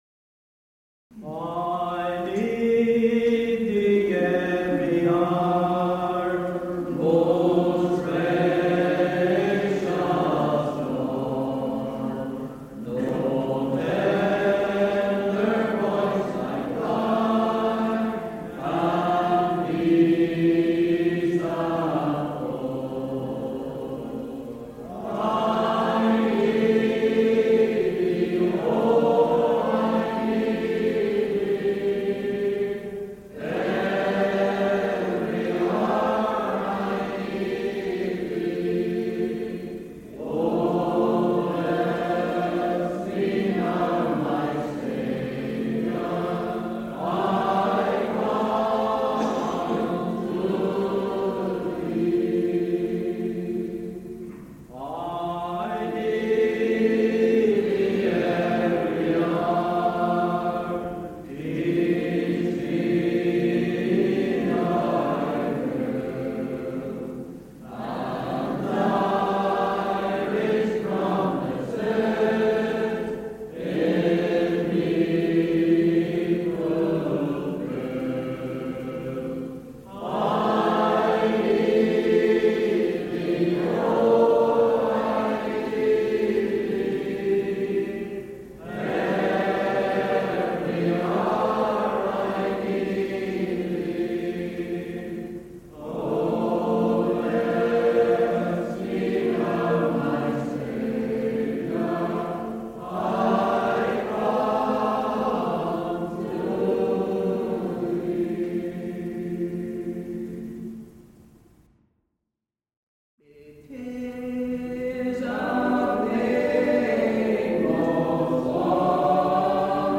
Singing from the 1990's and the 2,000's from Cambridge Avenue Gospel Hall, Ballymena.
Hymn singing